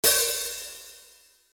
今回は、あらかじめ用意した HiHat 音を使います。
Open あるいは、ハーフOpenぎみの音です。
Hat_02.mp3